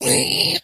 zpighurt1.mp3